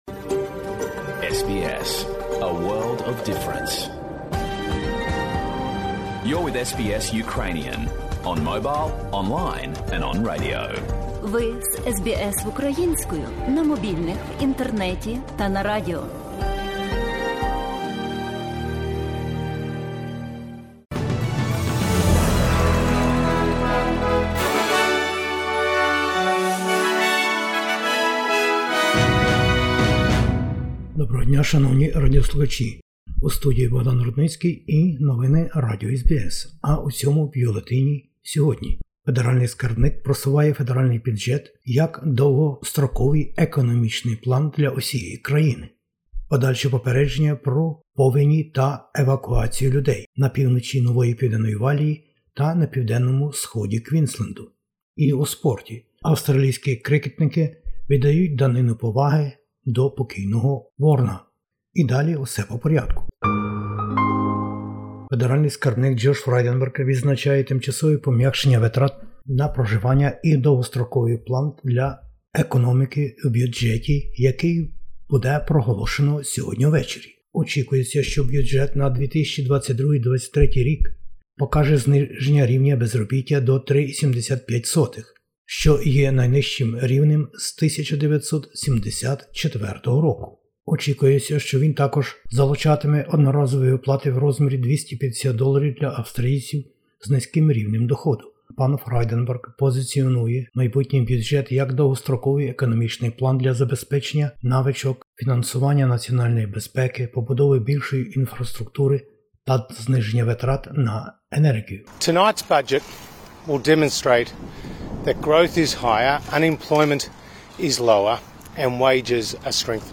Бюлетень новин SBS українською мовою. Федеральний бюджет Австралії буде оголошено нині ввечері. Загроза нових повеней у Новій Південній Валії та Квінсленді - нині і завтра. Війна в Україні - Президенти панове Зеленський і Байден.